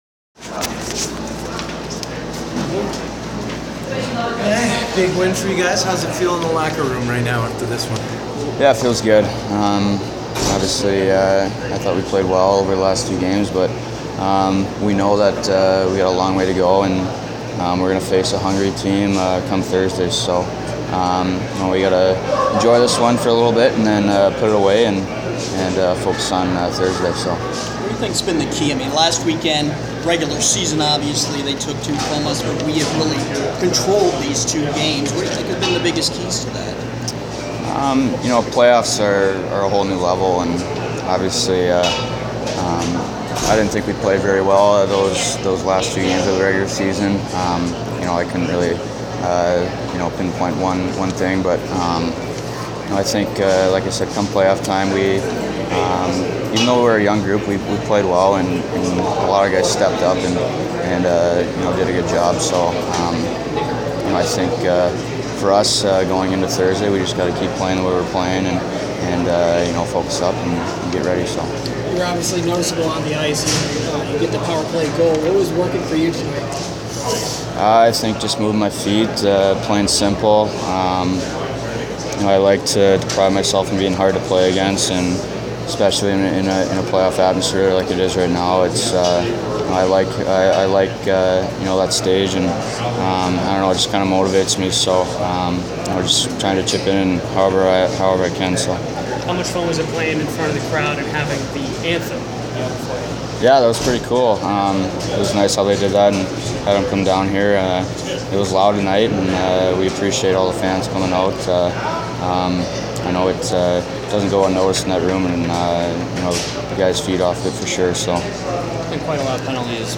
Postgame Reaction